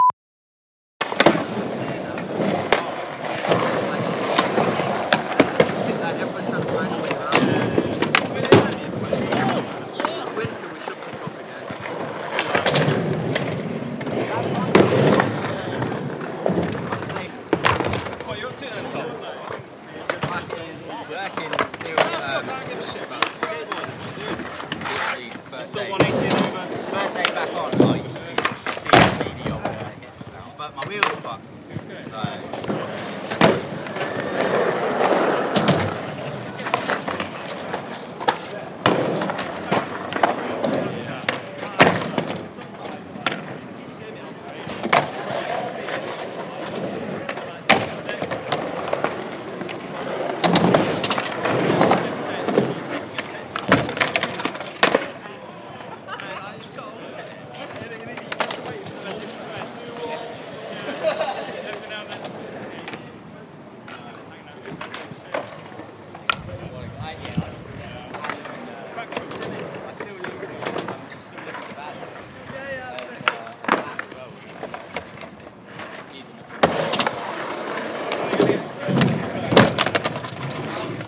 城市公园俄勒冈州波特兰市的飞机（噪音增长的BG）。
描述：这是俄勒冈州波特兰市中心城市公园的录音。有很多硬/水泥表面。 （很多红砖） 飞机发动机在整个赛道上爬行。
标签： 气氛 公园 次泰
声道立体声